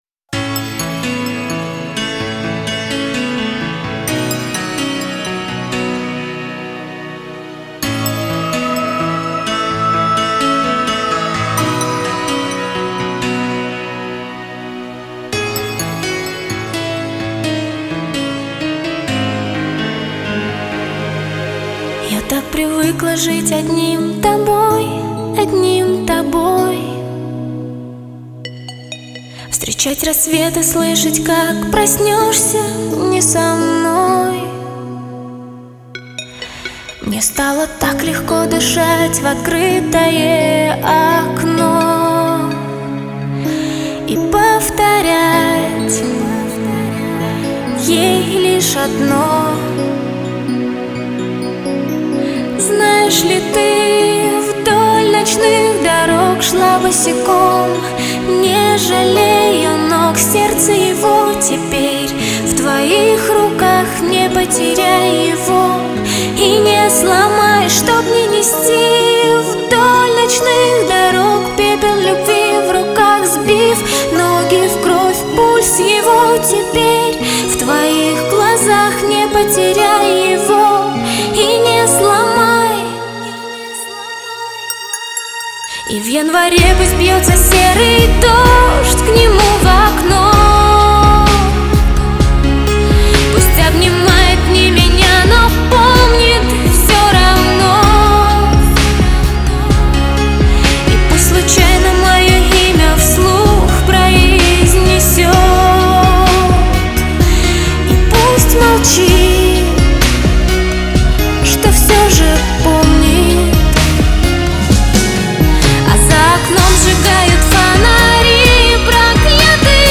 медленные песни
русские медляки